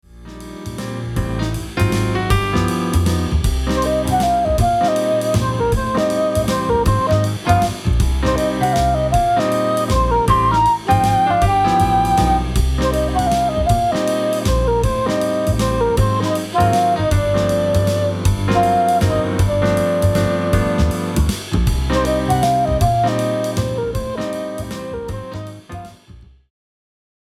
Range: Bb to high F.